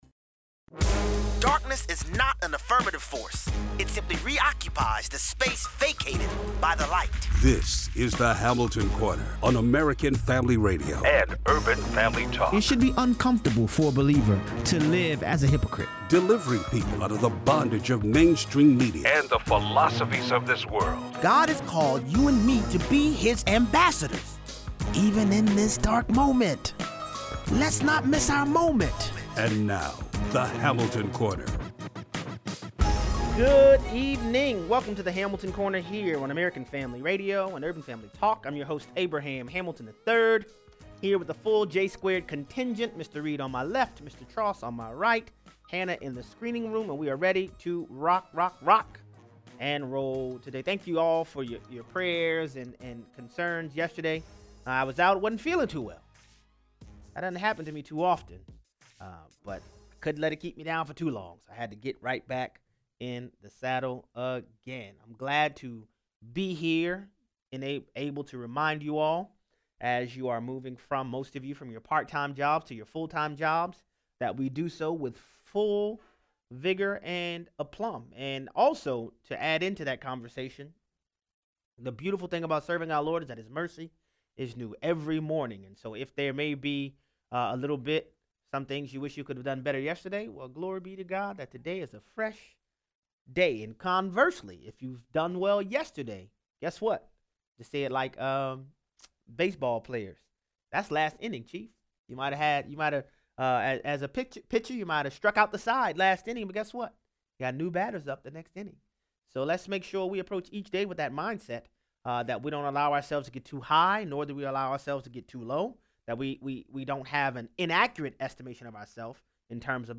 0:00 - 0:20: 1 Samuel 1:9-18 Earnestness and sincerity in prayer isn’t determined by vocal volume. 0:23 - 0:40: NY Times discloses an op-ed by an anonymous author. Cory Booker has a “Spartacus” moment with theatrical sincerity. 0:43 - 0:60: Kamala Harris implies Cavanaugh is colluding with a Trump law firm. Callers weigh in.